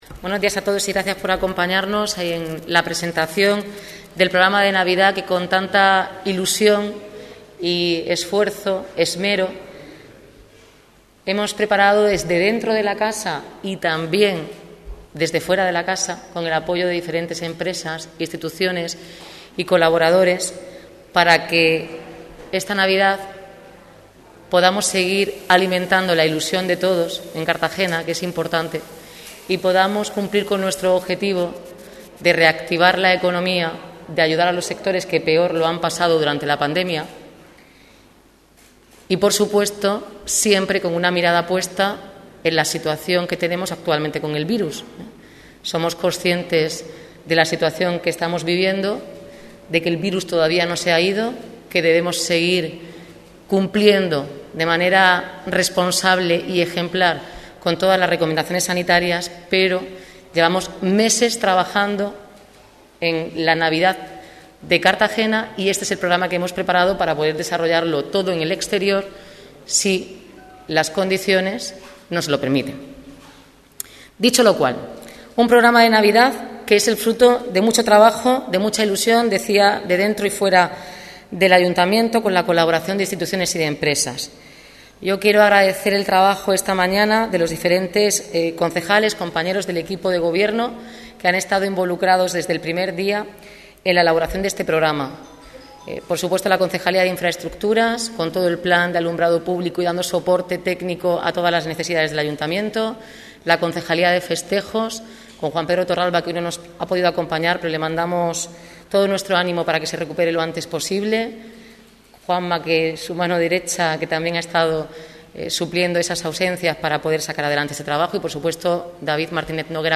Audio: Presentaci�n de la Programaci�n de Navidad (MP3 - 12,40 MB)